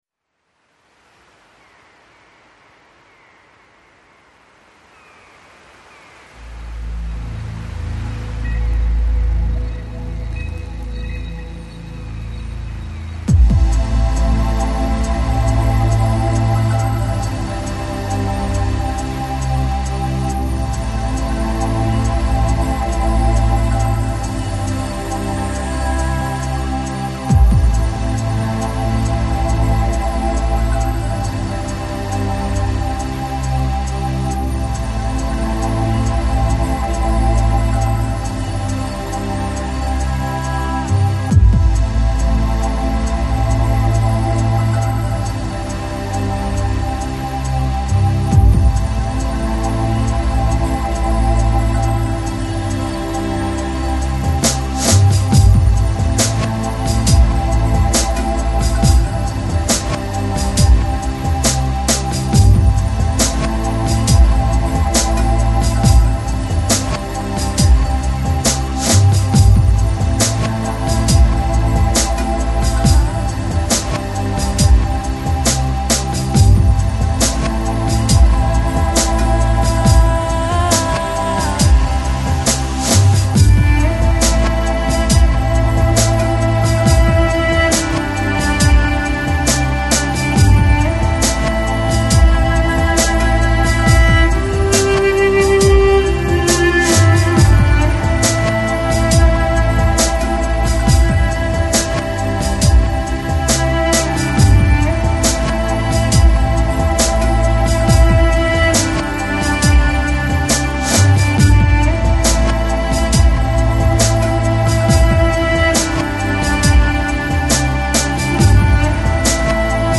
Downtempo, Lounge, Chillout, Ambient Носитель